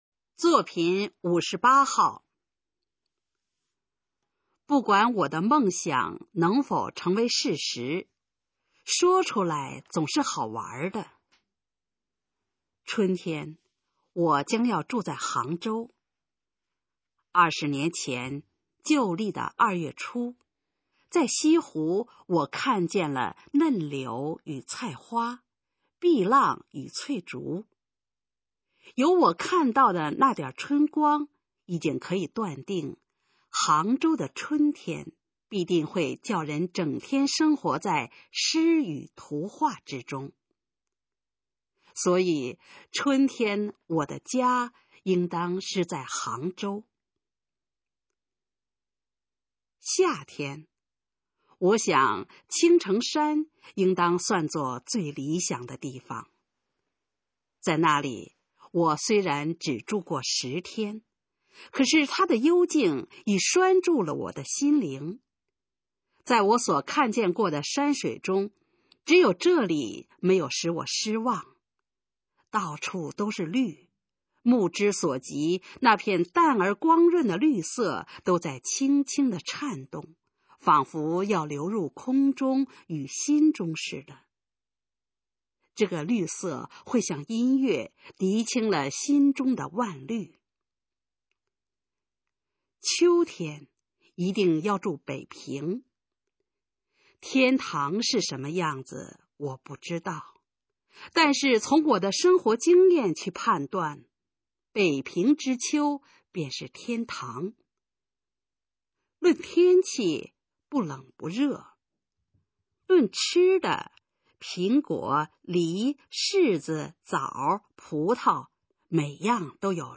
《住的梦》示范朗读